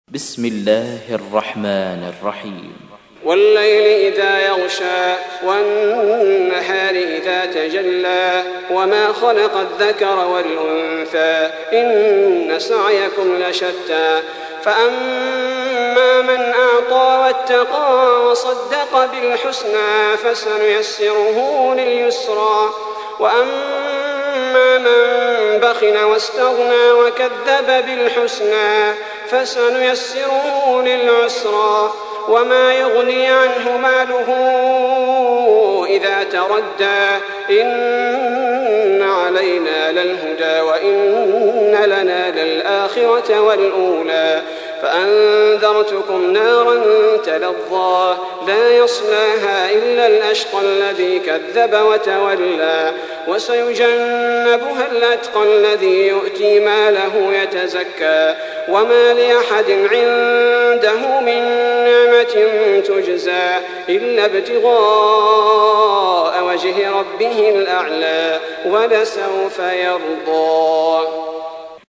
تحميل : 92. سورة الليل / القارئ صلاح البدير / القرآن الكريم / موقع يا حسين